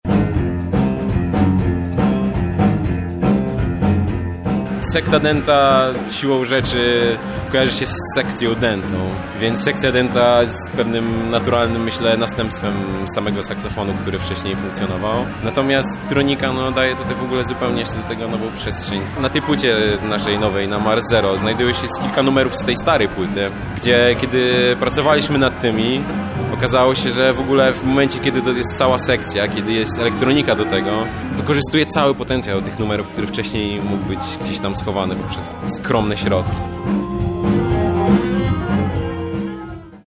saksofonista